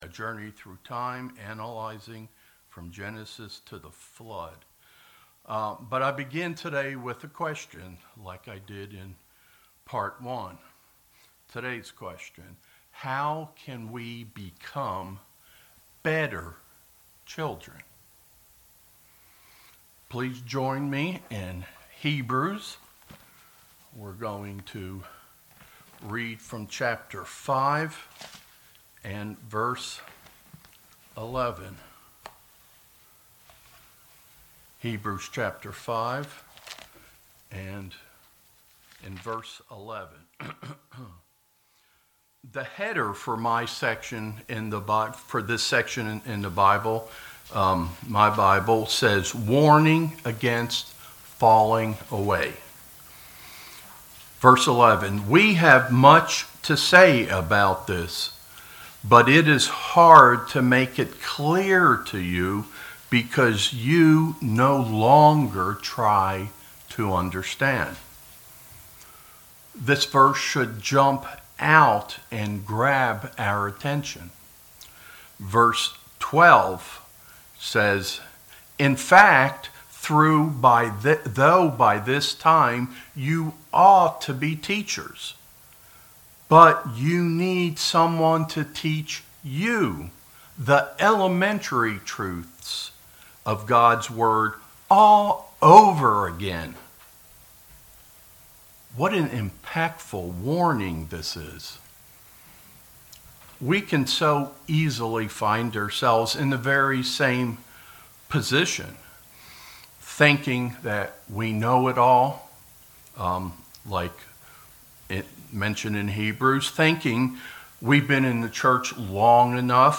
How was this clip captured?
Given in Knoxville, TN